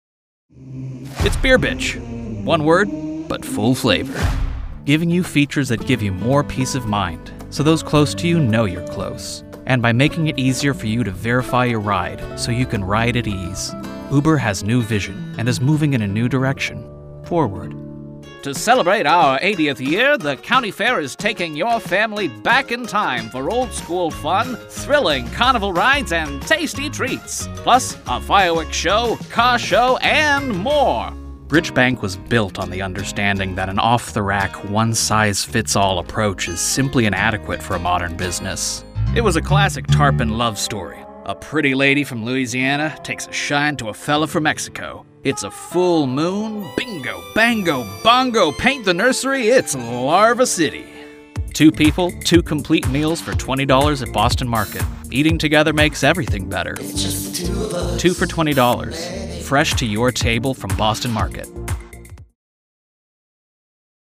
Commercial VO Reel